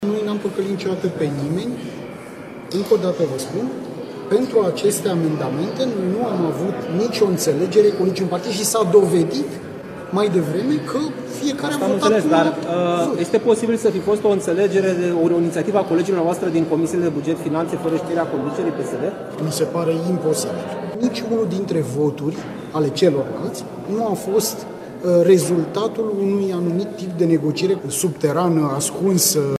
PSD nu a avut niciun fel de negociere cu partidele din opoziție privind amendamentele la buget referitoare la măsurile sociale, a declarat ministrul Muncii, Florin Manole. Întrebat dacă ceilalți colegi de partid ar fi vorbit pe ascuns și ar fi votat pe baza unei înțelegeri, ministrul a negat o astfel de inițiativă.
Ministrul Muncii, Florin Manole: „Noi nu am păcălit niciodată pe nimeni”